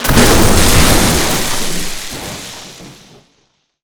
electric_lightning_blast_06.wav